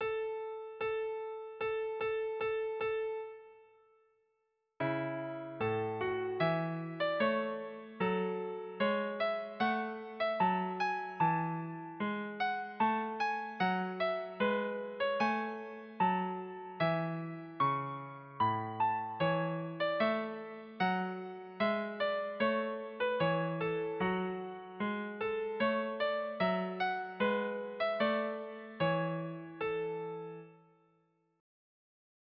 Kleines Übungsstück 1 in A-Dur für Violine
Digitalpiano Casio CDP-130